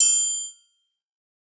DY Trap Triangle (1).wav